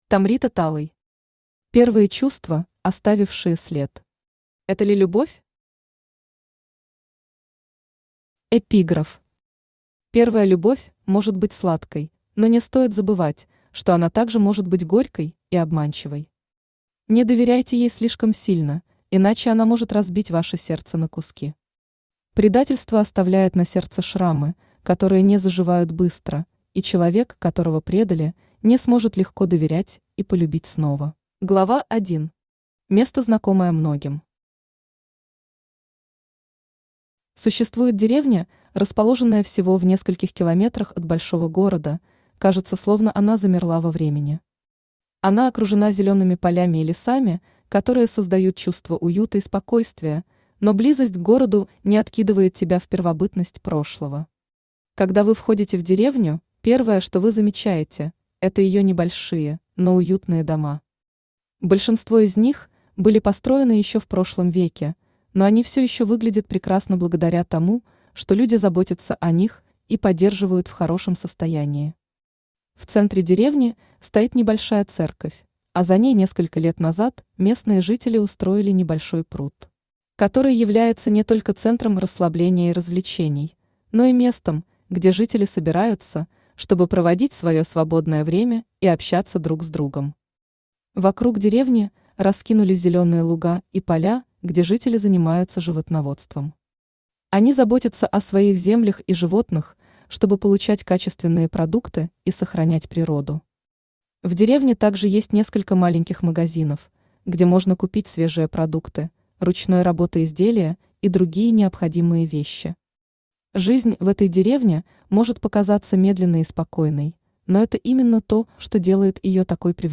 Аудиокнига Первые чувства, оставившие след. Это ли любовь?